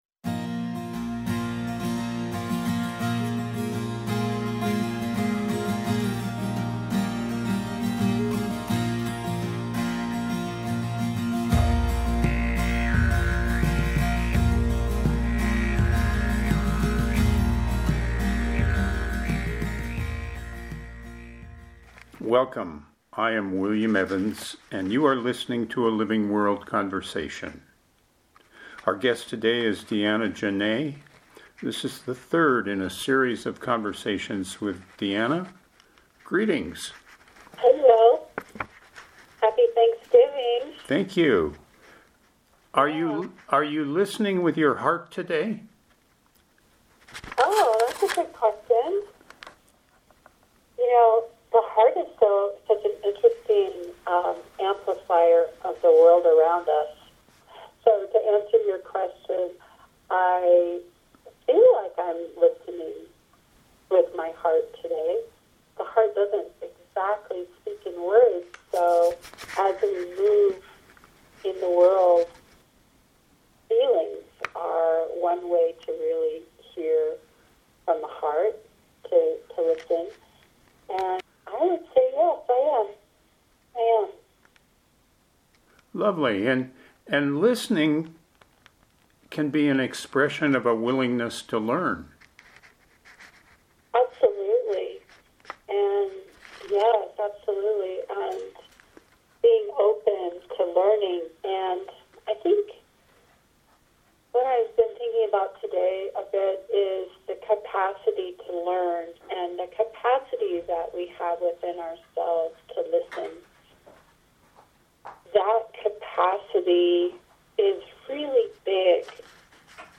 Shifting Gears features conversations with people making life-sustaining choices.